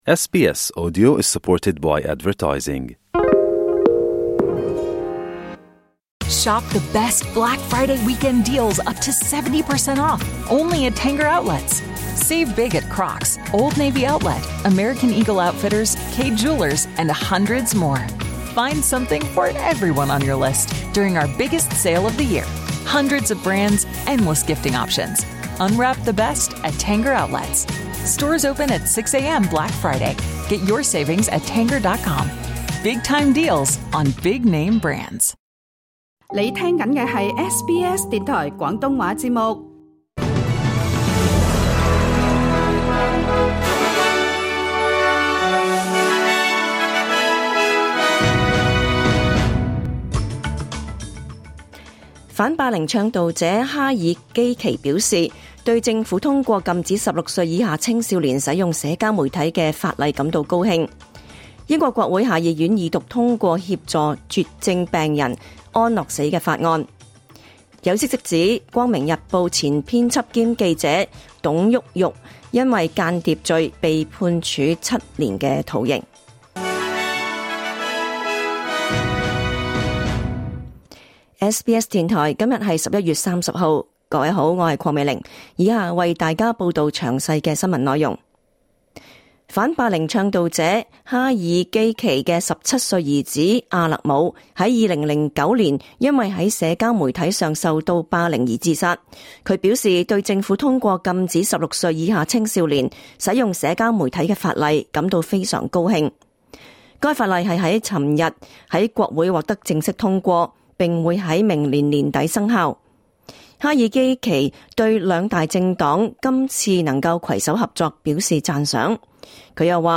2024 年 11 月 30 日 SBS 廣東話節目詳盡早晨新聞報道。